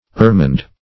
Ermined \Er"mined\, a.